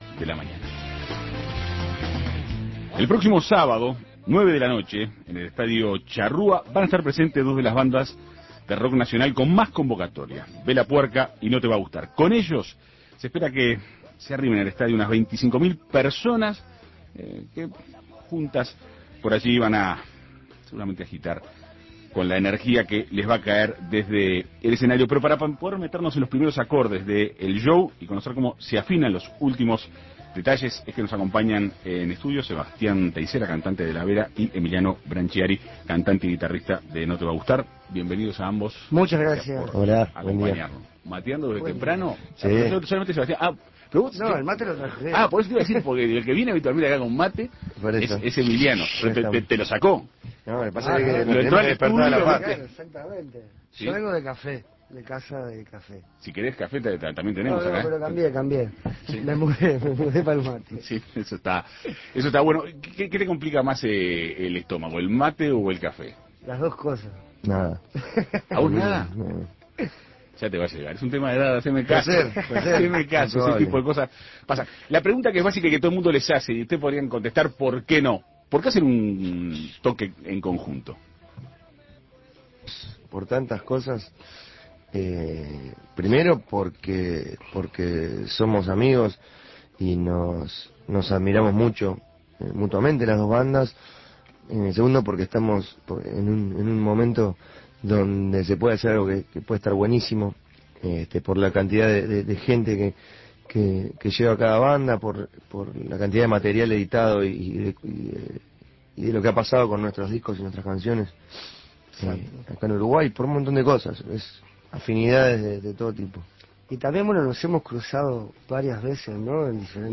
Entrevista con Emiliano Brancciari y Sebastián Teysera.